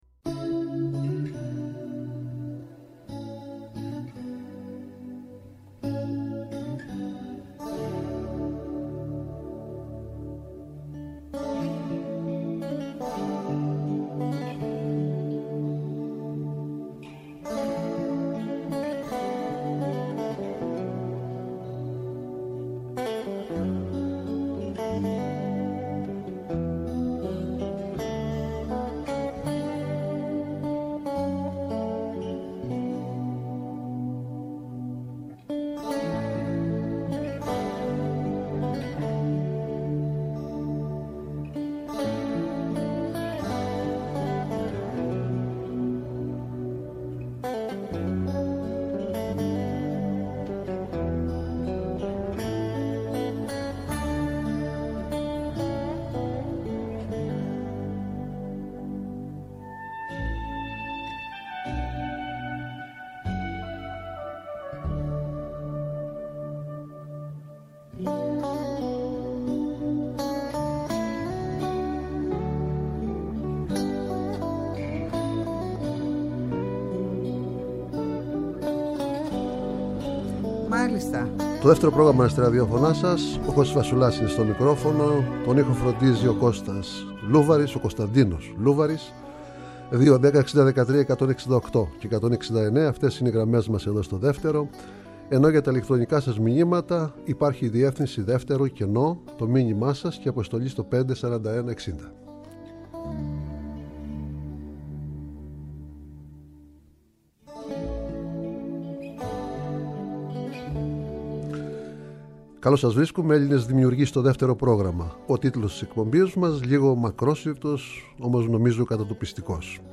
Η κορυφαία Ελληνίδα στιχουργός Λίνα Νικολακοπούλου έρχεται στο Δεύτερο Πρόγραμμα 103,7, το Σάββατο 13 Ιουνίου, 14:00-15:00, έτοιμη να μας διηγηθεί ιστορίες που συνοδεύουν τα τραγούδια της, σκέψεις και θέσεις για το μουσικό μας πολιτισμό ενώ θα διαβάσει για πρώτη φορά αποσπάσματα μέσα από αγαπημένα της λογοτεχνικά έργα.
Συνεντεύξεις